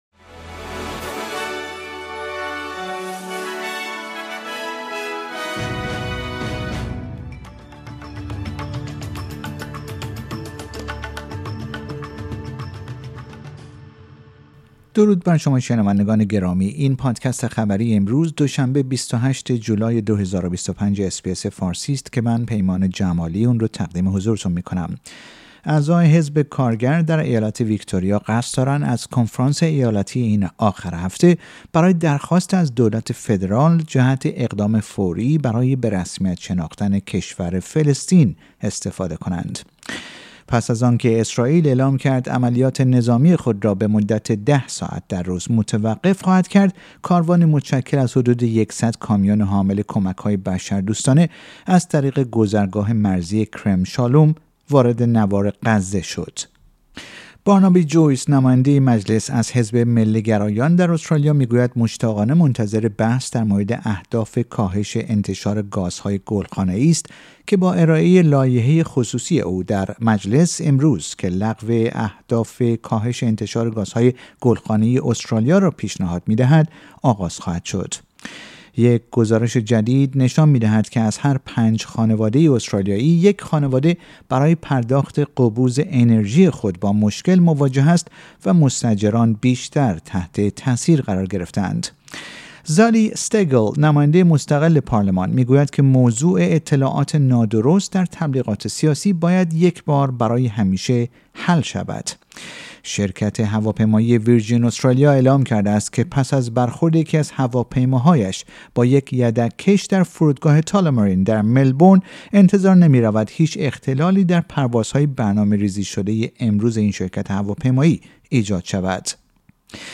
در این پادکست خبری مهمترین اخبار امروز دوشنبه ۲۸ جولای ارائه شده است.